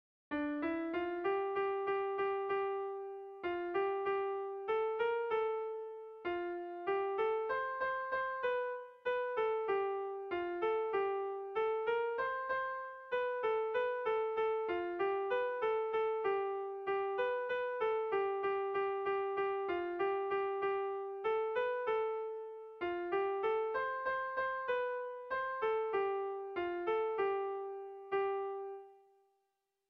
Tragikoa
Hamarreko txikia (hg) / Bost puntuko txikia (ip)
ABDEB